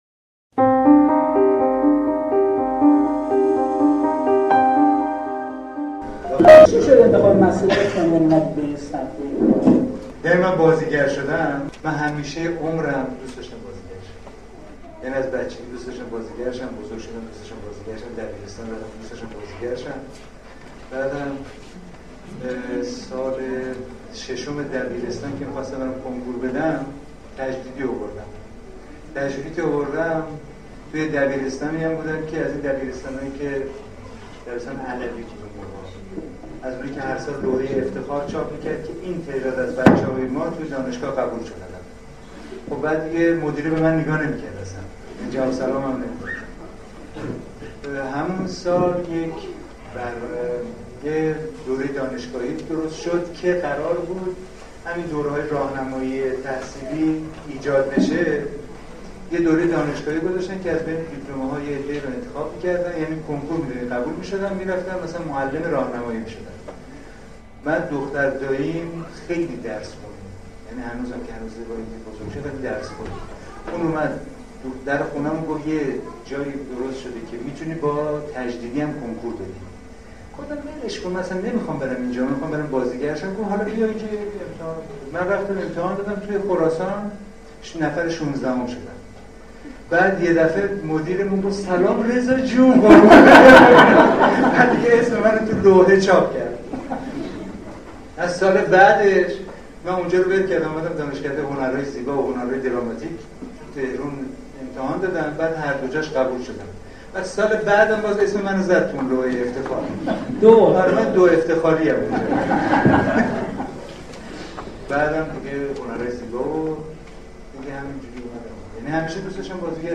گفتگوی رضا کیانیان با بنیاد فرهنگ زندگی | بنیاد فرهنگ زندگی
کیفیت صدا مثل همیشه افتضاح